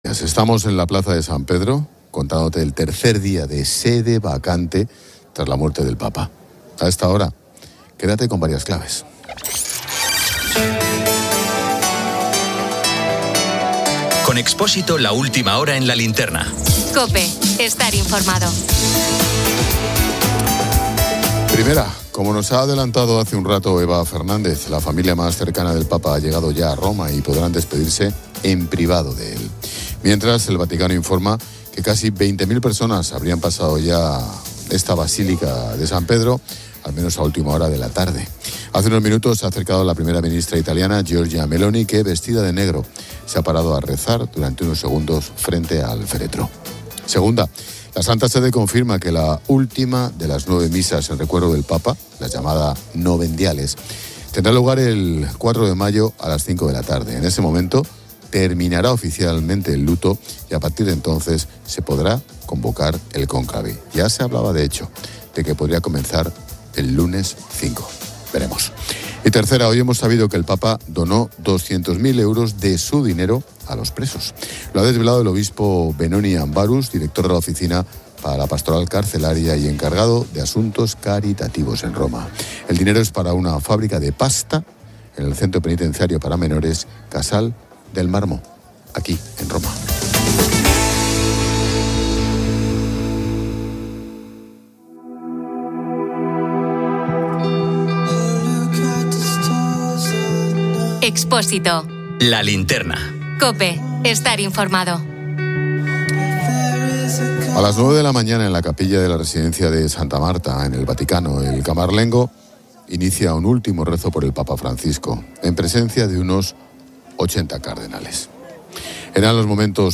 Estamos en la plaza de San Pedro, contándote el tercer día de sede vacante tras la muerte del papa.